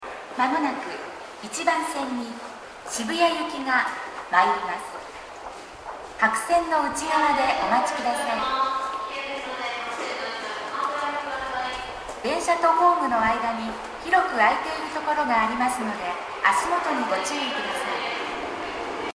スピーカーは天井型で音質は比較的いいです。銀座線ホームは天井もやや低めなので収録がしやすいです。
接近放送各駅停車　渋谷行き接近放送です。